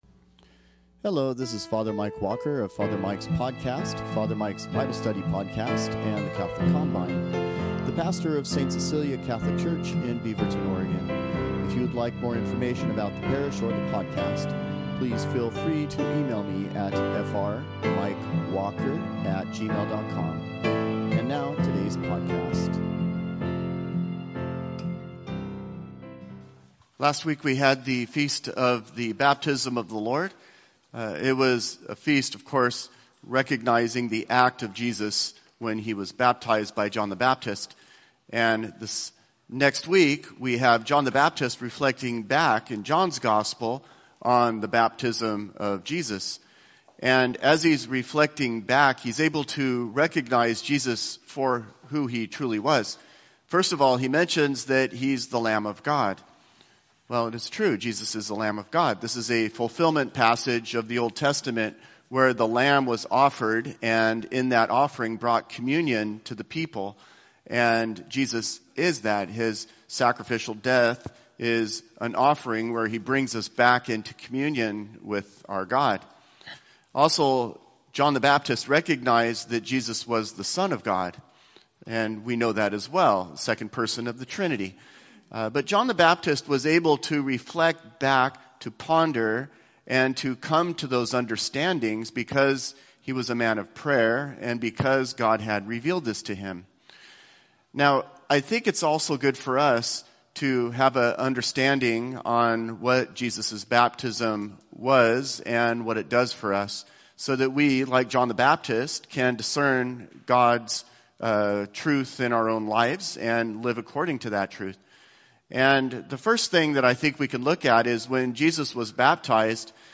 Sunday homilies